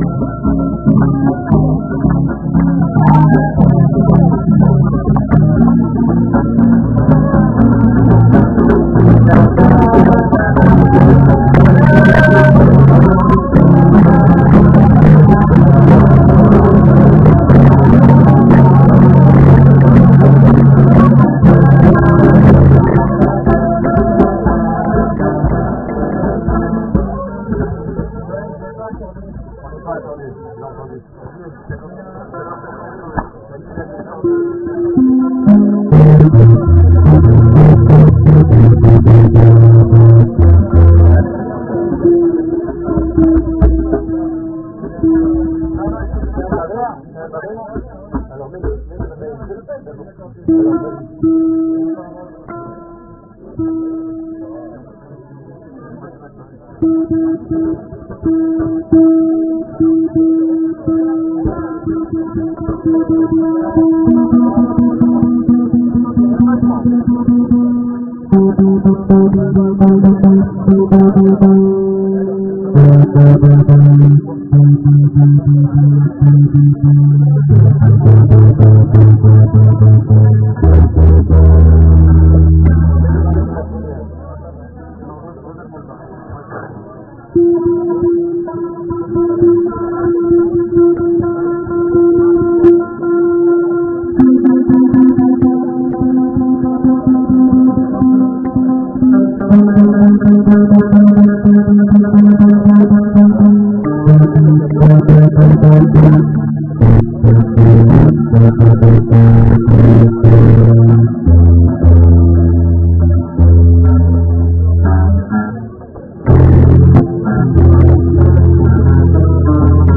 שיעורים